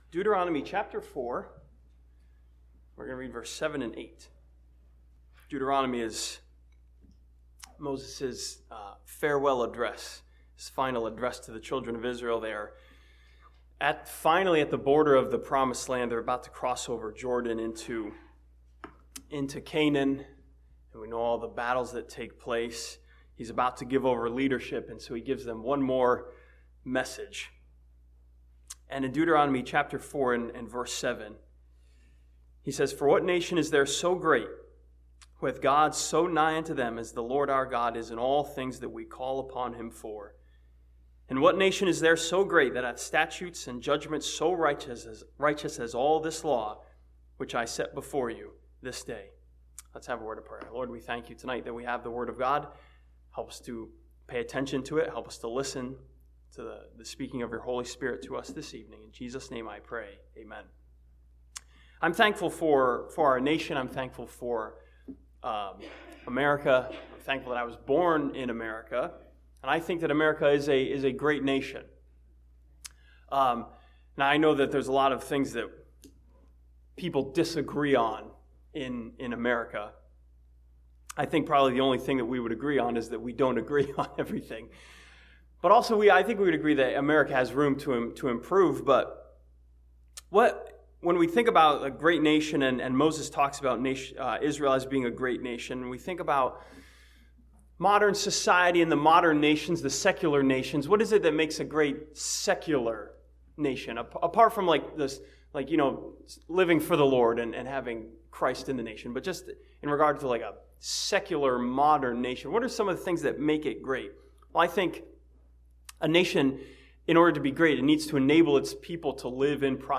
This sermon from Deuteronomy chapter 4 studies the causes of greatness and how they can be applied to Christians.